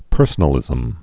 (pûrsə-nə-lĭzəm)